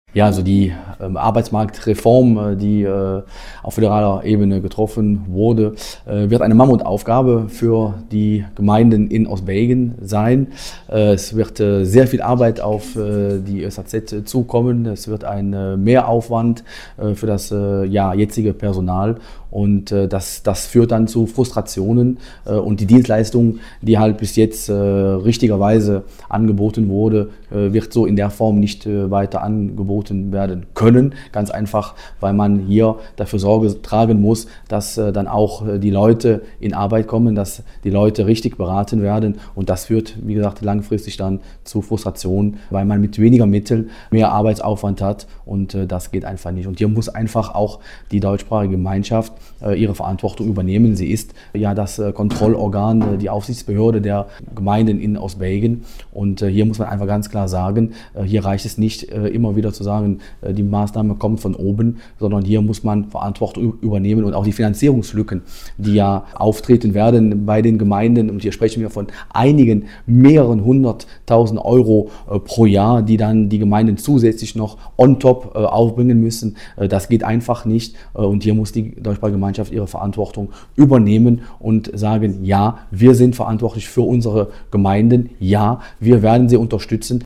Sorgen macht der SP auch die Arbeitsmarktreform der Föderalregierung. Vize-Fraktionsvorsitzender Björn Klinkenberg.